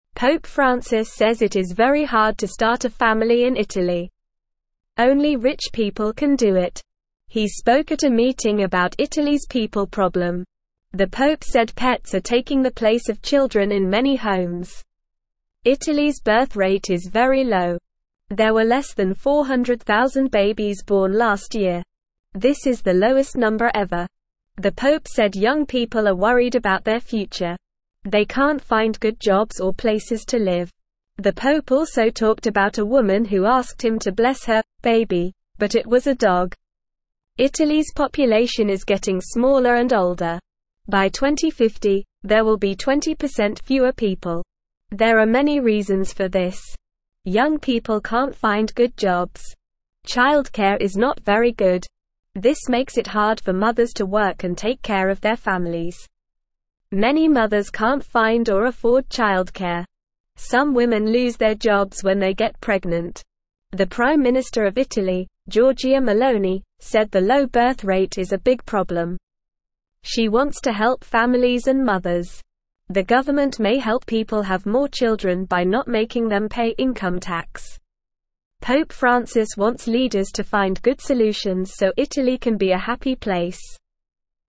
Normal
English-Newsroom-Beginner-NORMAL-Reading-Pope-Talks-About-Italys-Baby-Problem.mp3